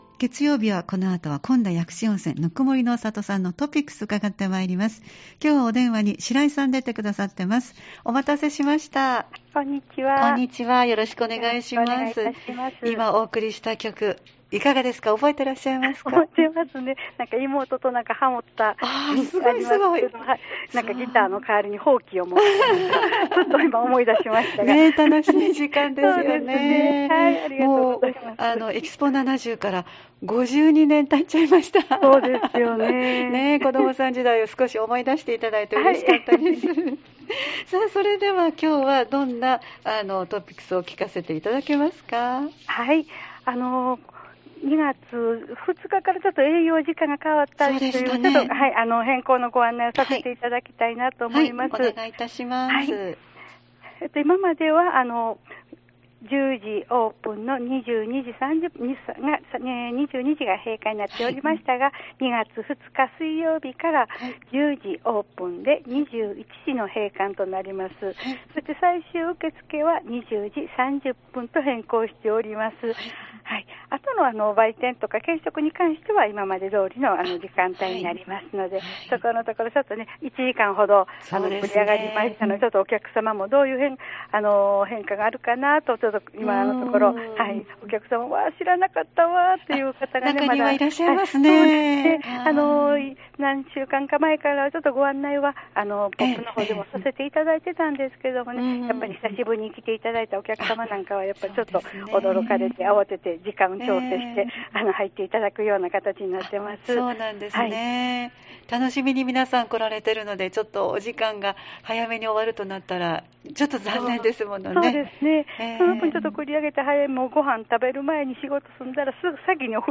毎週月曜日16時台は丹波篠山市にある「こんだ薬師温泉ぬくもりの郷」に電話をつないで、スタッフの方からイベントや企画、タイムリーな情報を紹介してもらっています。